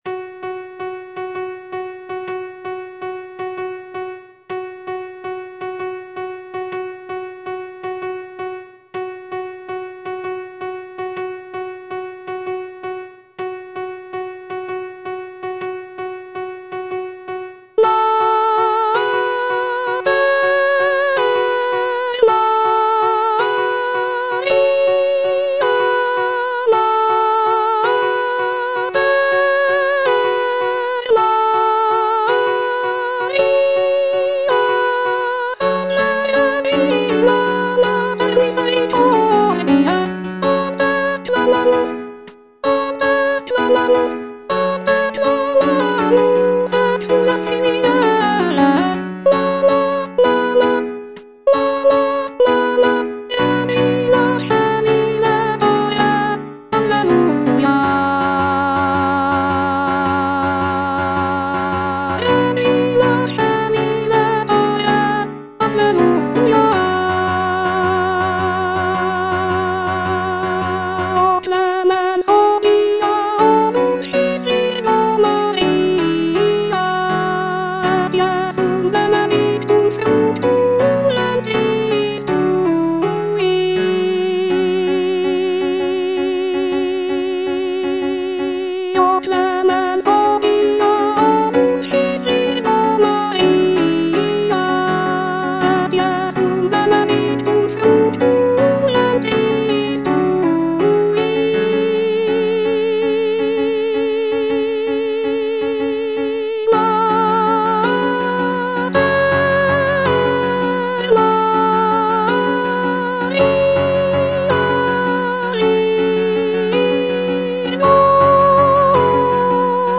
Soprano I (Voice with metronome) Ads stop